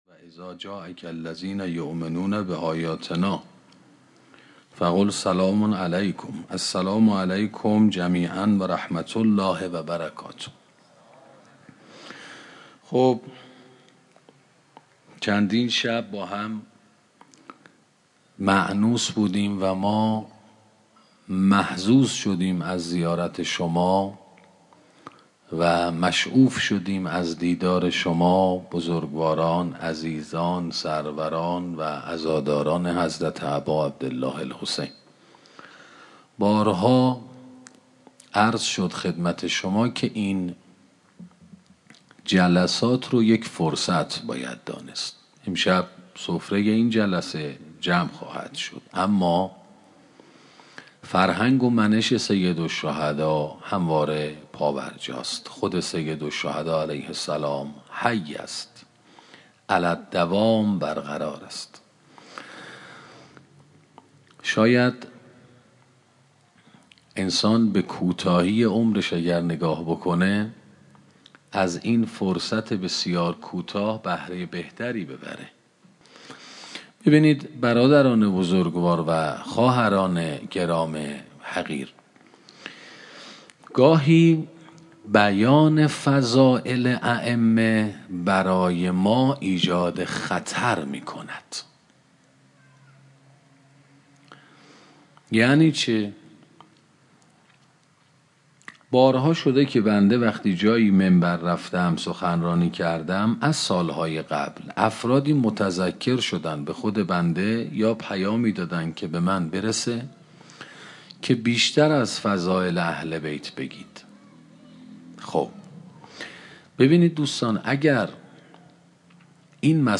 16 سخنرانی شرح زیارت عاشورا